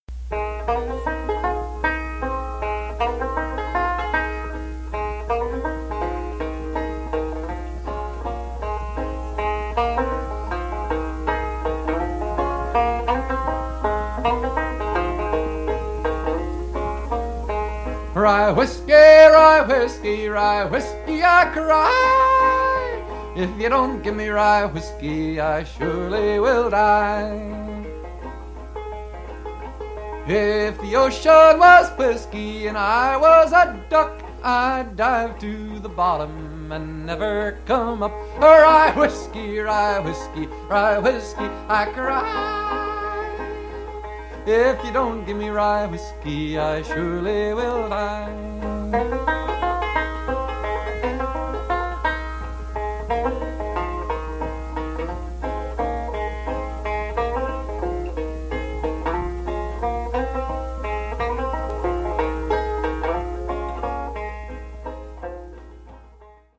Traditional
This song is in 3/4 waltz time.
Listen to Pete Seeger perform "Rye Whiskey" (mp3)